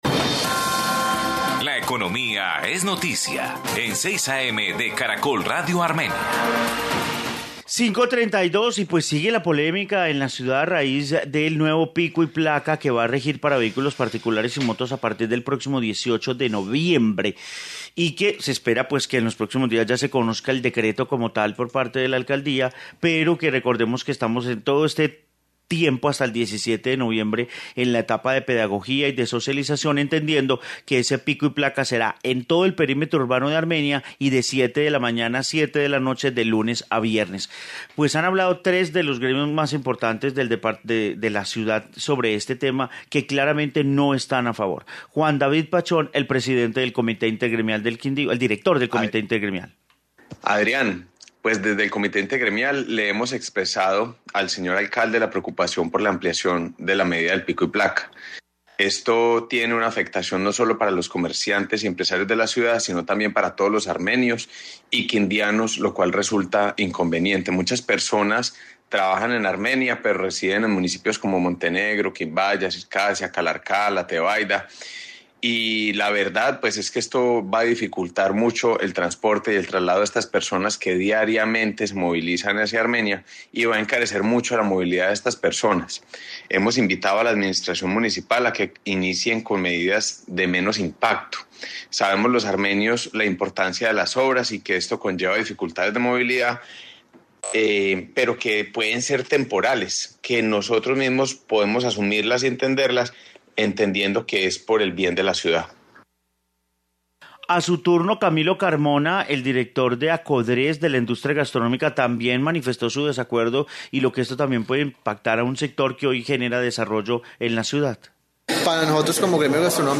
Informe pico y placa gremios en Armenia